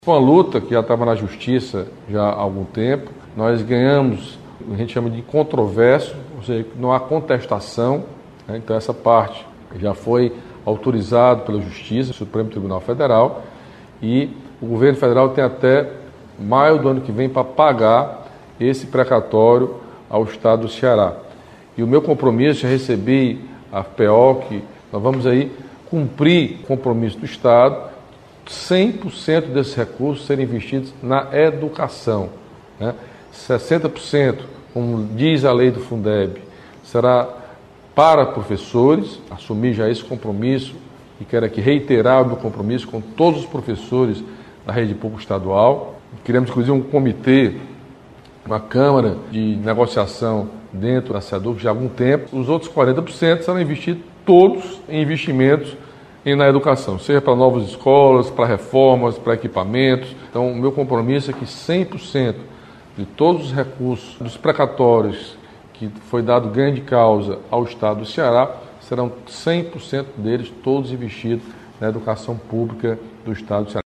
Durante bate-papo semanal com internautas foi sancionada também a lei que amplia o Parque Estadual Marinho da Pedra da Risca do Meio
Em live semanal nas redes sociais, o governador Camilo Santana assinou, nesta terça-feira (21), a convocação imediata de mais 60 professores aprovados no concurso da Secretaria da Educação (Seduc).